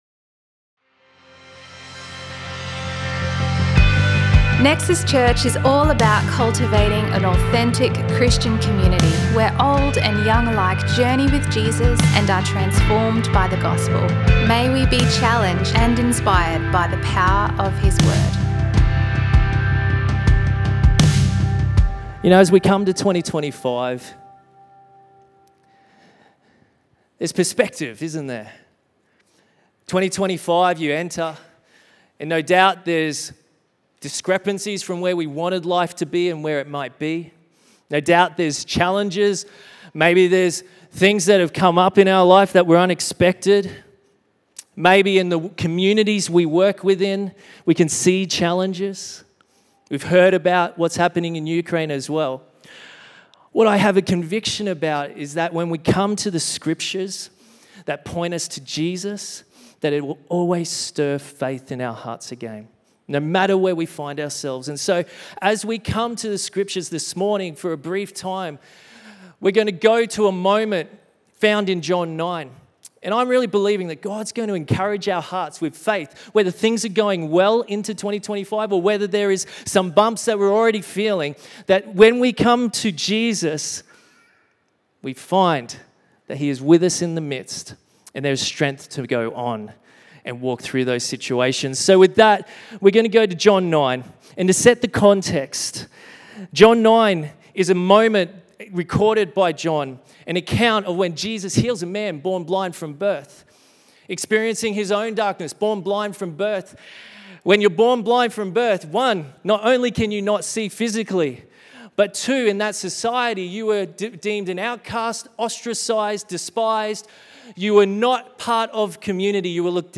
A selection of messages from Nexus Church in Brisbane, Australia.